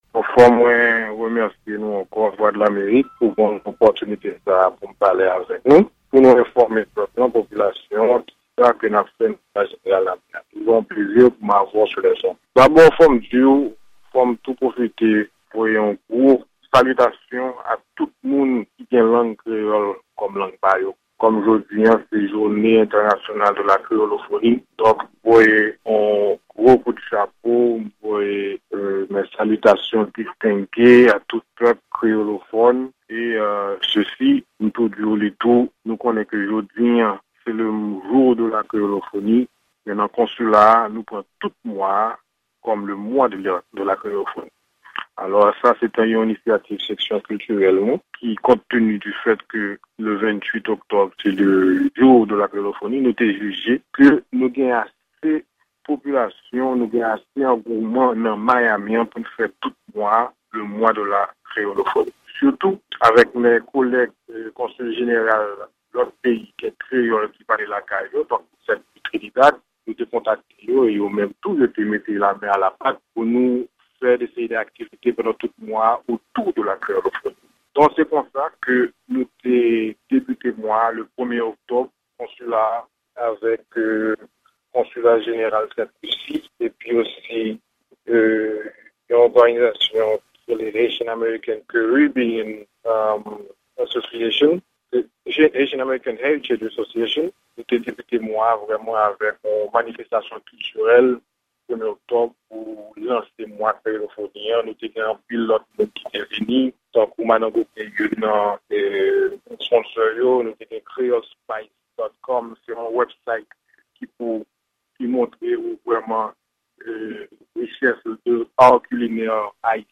Entèvyou